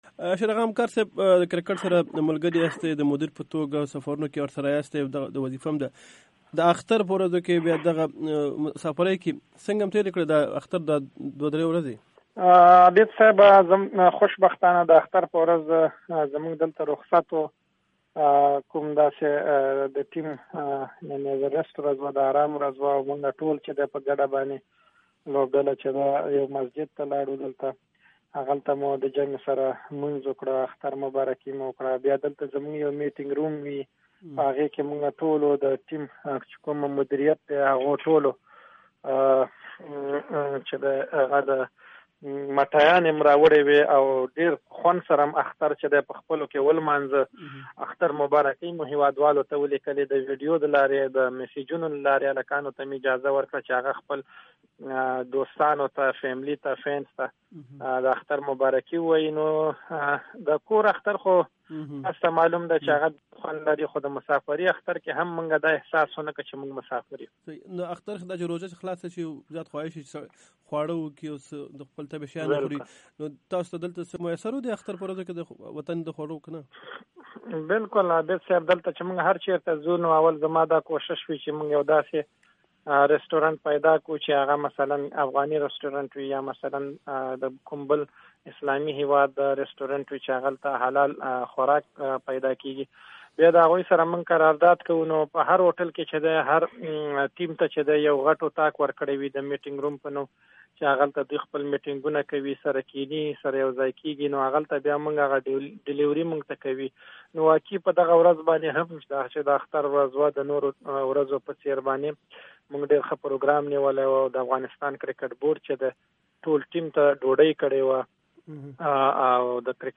cricket eid interview